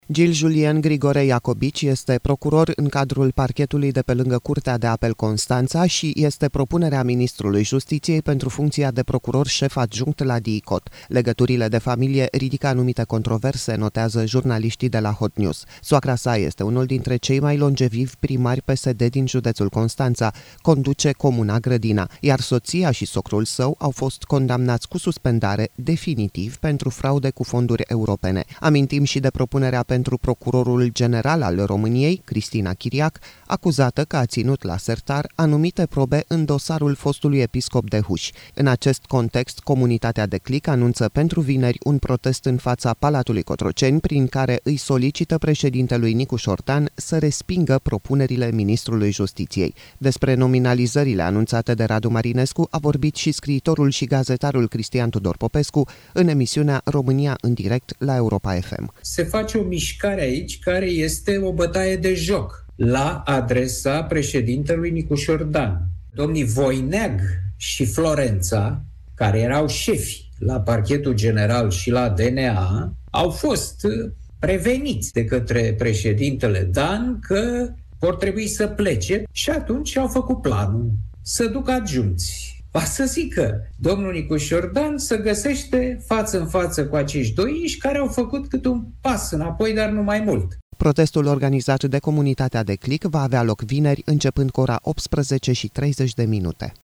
Despre nominalizările anunțate de Radu Marinescu a vorbit și scriitorul și gazetarul Cristian Tudor Popescu în emisiunea România în Direct, la Europa FM: